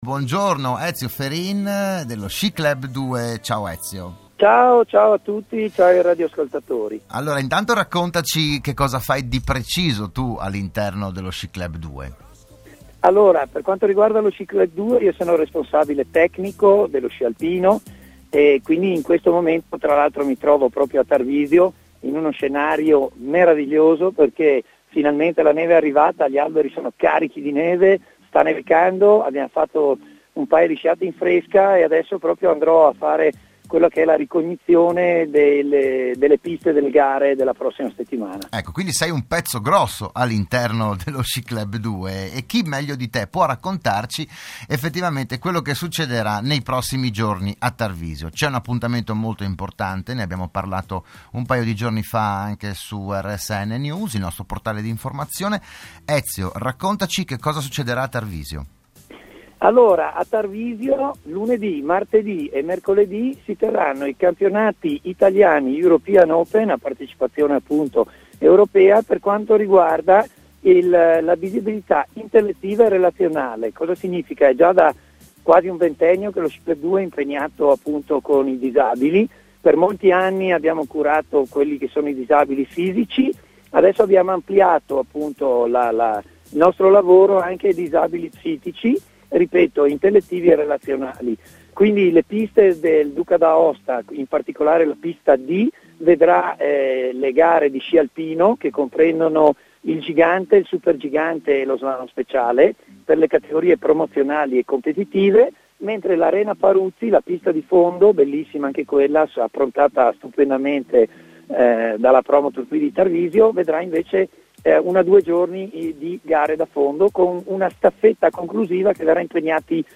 Dal 16 al 19 gennaio l'evento promosso dalla federazione Italiana sport disabilità intellettiva e relazionale. Il PODCAST dell'intervento a Radio Studio Nord